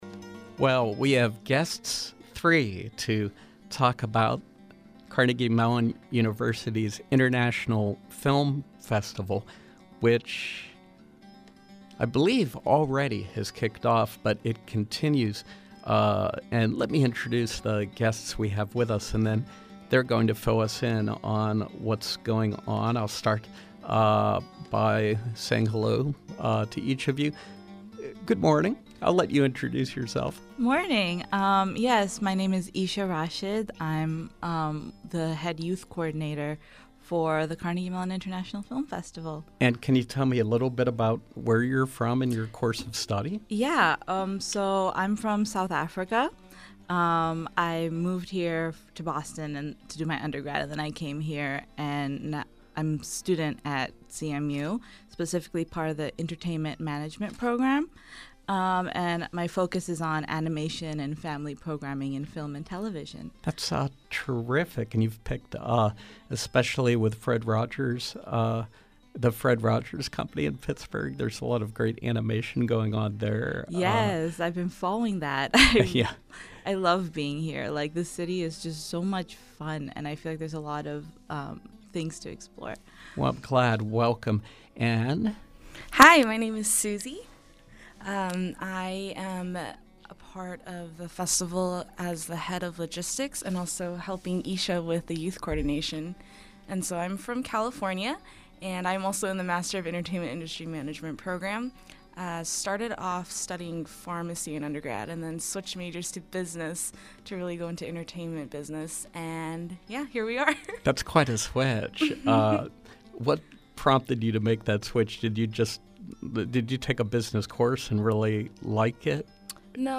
In Studio Pop-Up: CMU International Film Festival